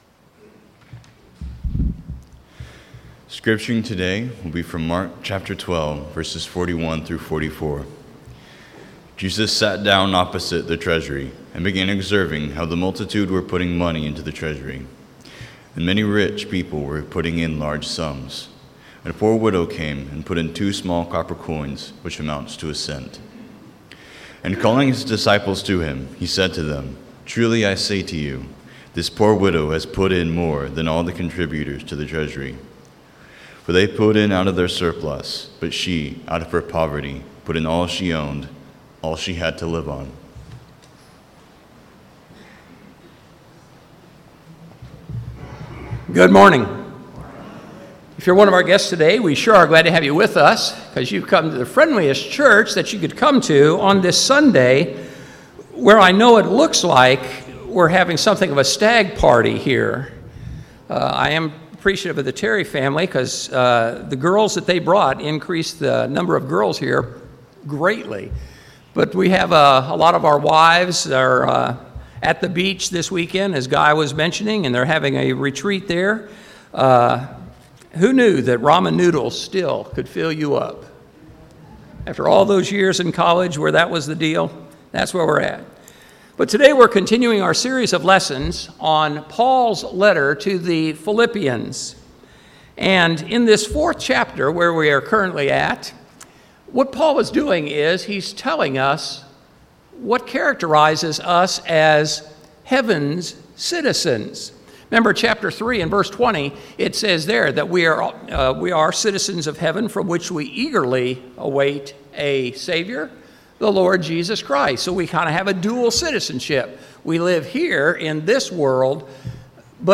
Sunday AM Sermon : Blessed Assurance Lesson #2: Assurance through the Spirit Weekly Bulletin CLICK HERE !!!